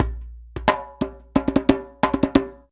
089DJEMB15.wav